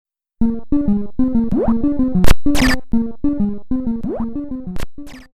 Arcade[edit]